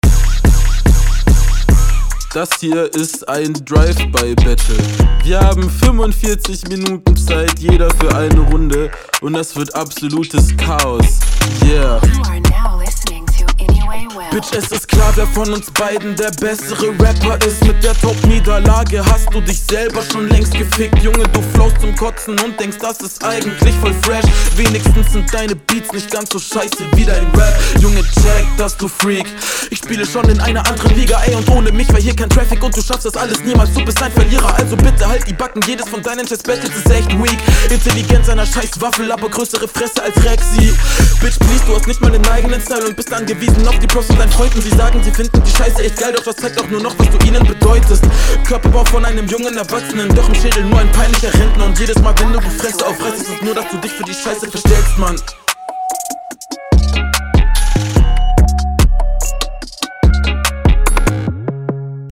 Schnelles Battle Format